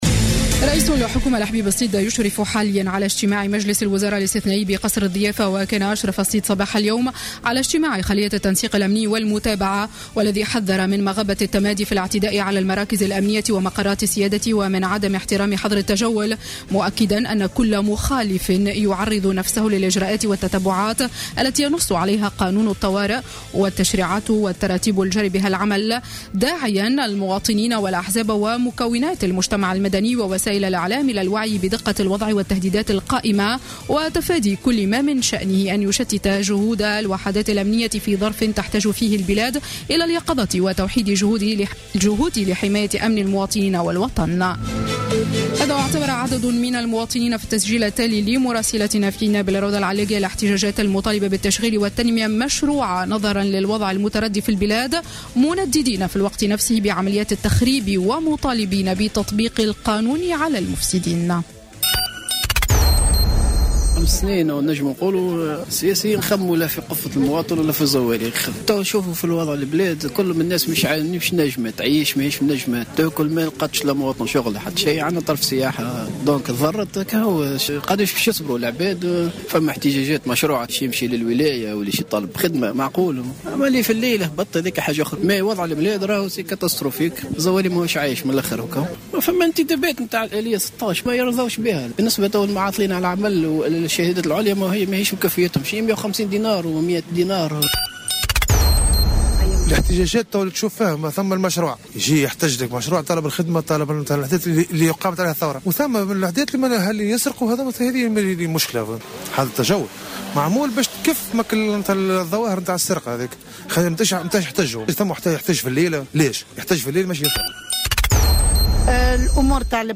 نشرة أخبار منتصف النهار ليوم السبت 23 جانفي 2016